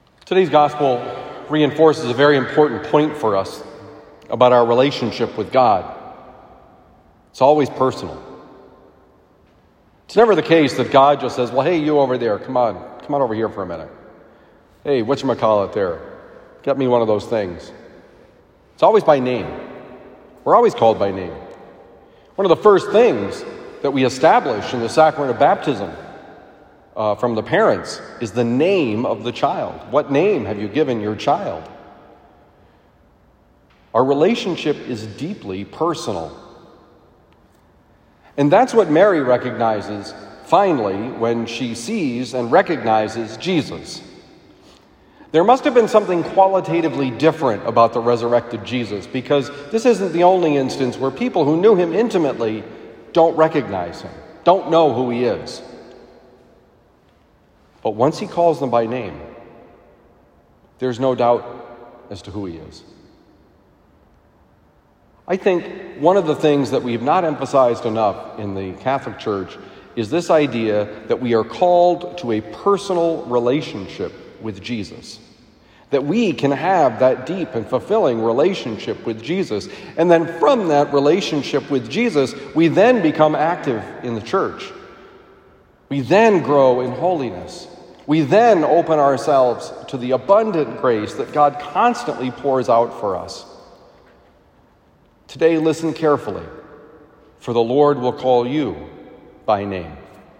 Homily for Tuesday, April 6, 2021
Given at Christian Brothers College High School, Town and Country, Missouri.